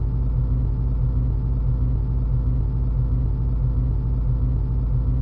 A320_cockpit_starter.wav